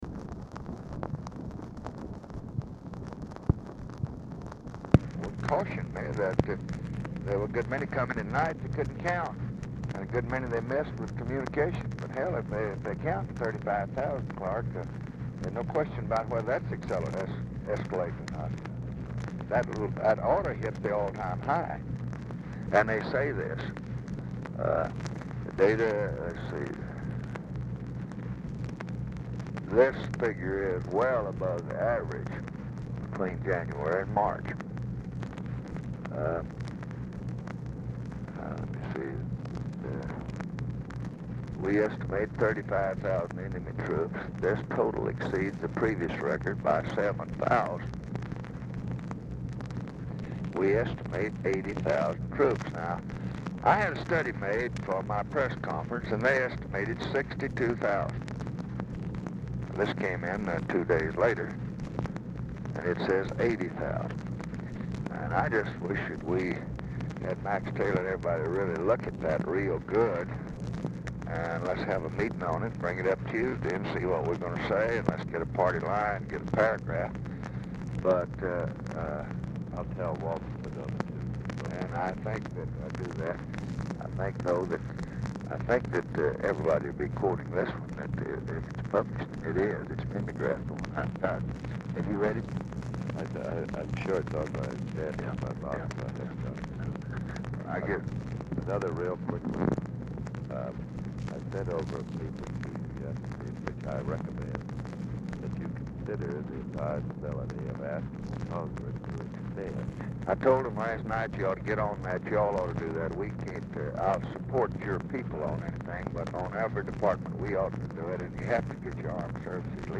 CLIFFORD IS DIFFICULT TO HEAR
Format Dictation belt
Specific Item Type Telephone conversation